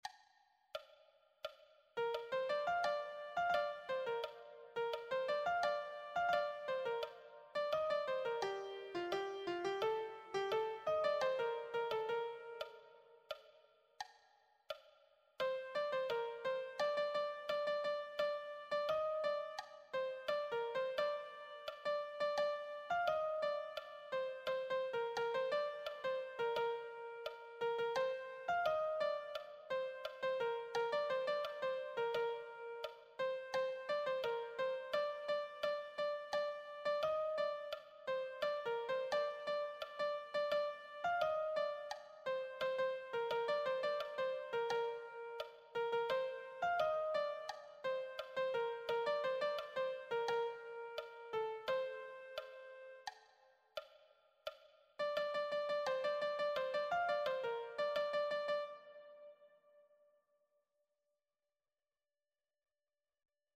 Transcribed in the original key of Bb major.